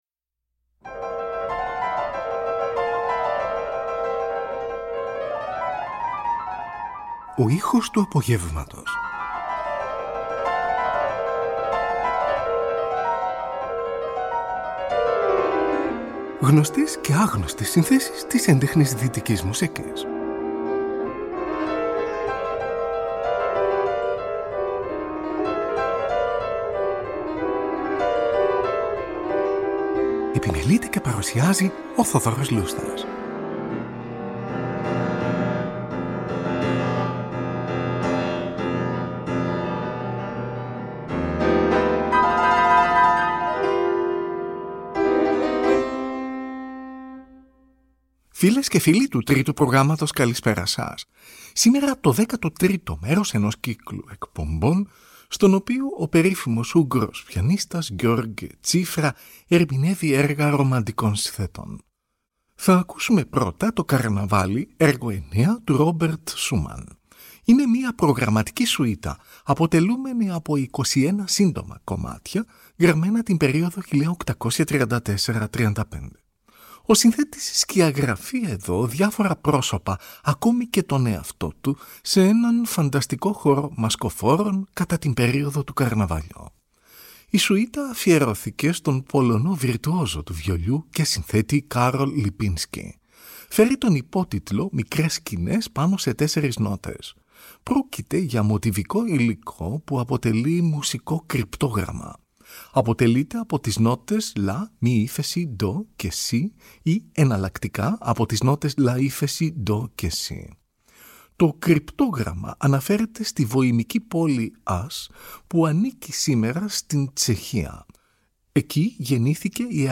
O Ούγγρος Πιανίστας György Cziffra Ερμηνεύει Έργα Ρομαντικών Συνθετών – 13o Μέρος | Τετάρτη 26 Φεβρουαρίου 2025
Πιανιστας
Ρομαντισμος